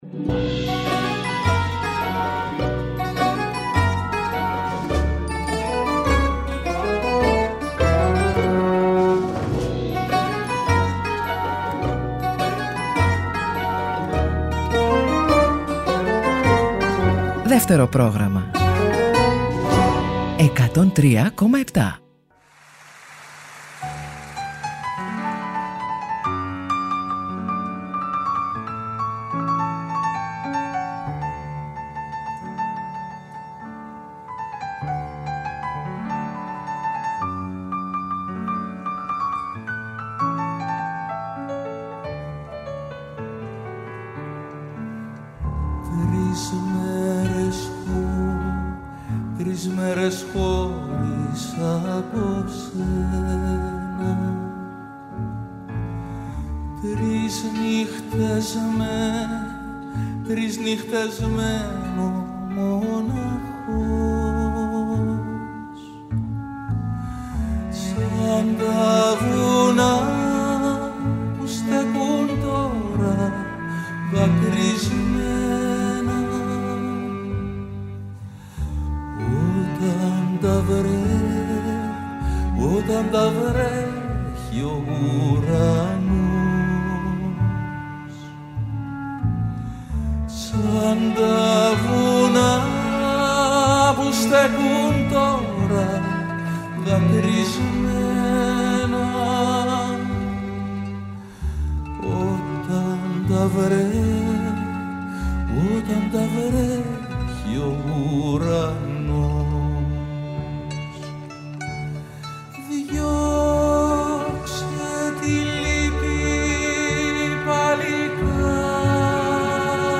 Στο “Μελωδικό Αντίδοτο” oι καινούριες μουσικές κάνουν παρέα με τις παλιές αγαπημένες κάθε σαββατοκύριακο.